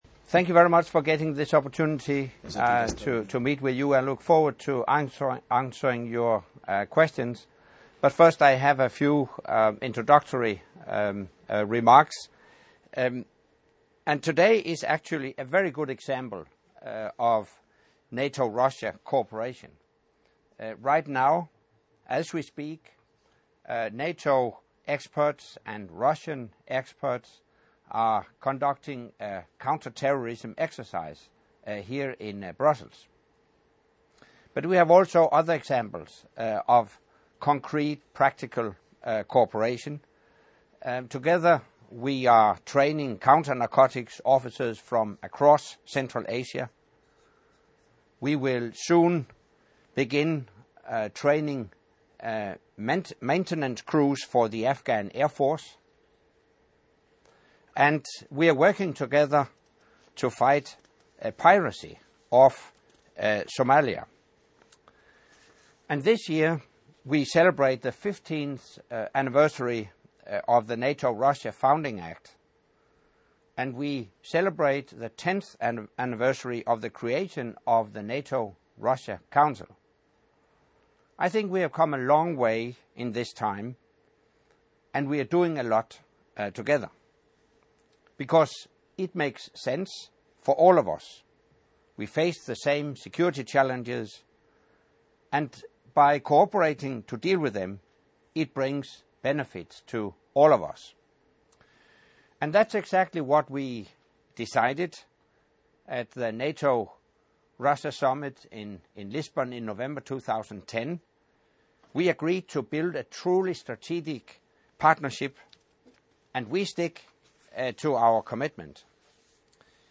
Press conference by NATO Secretary General Anders Fogh Rasmussen with Moscow-based journalists